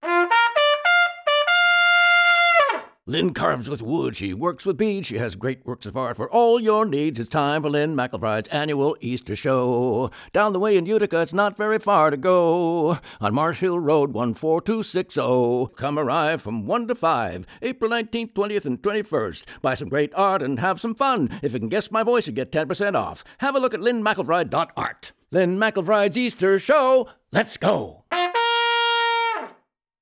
ad-with-trumpet.wav